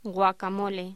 Locución: Guacamole
voz